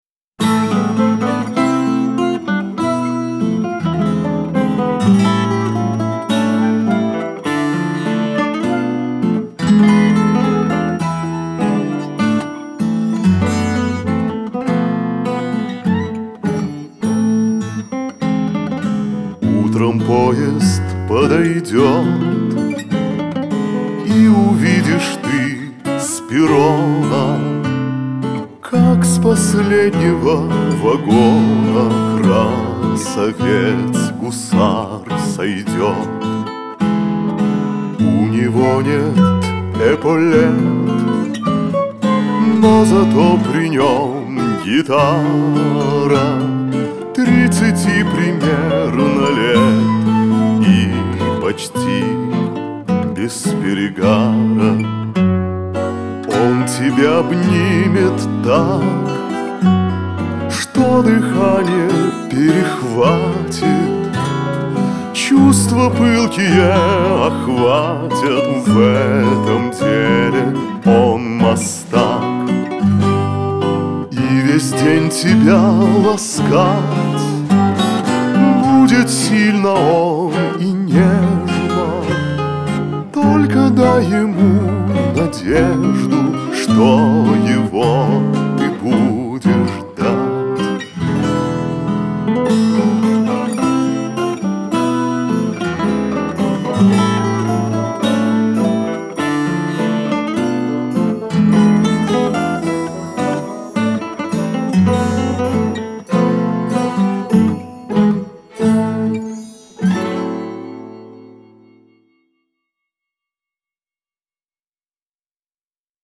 гитара
клавишные